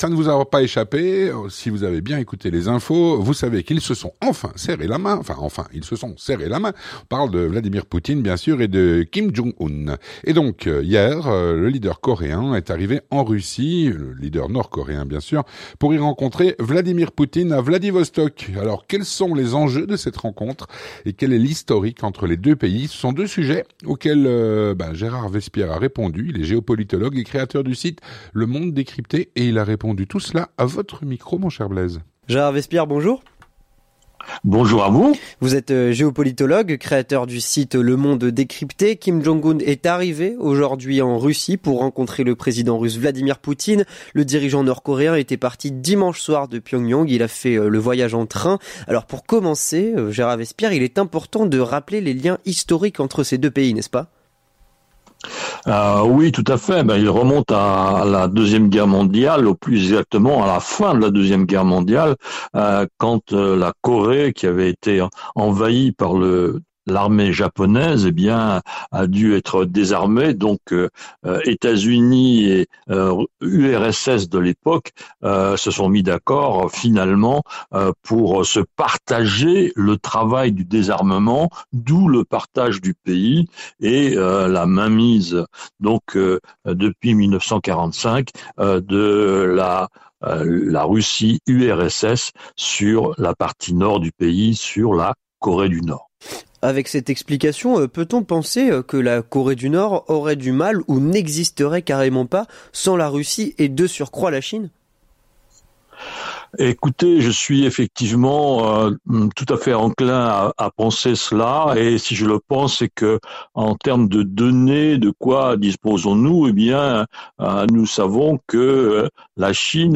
L'entretien du 18H - Rencontre Poutine/ Kim Jong Un.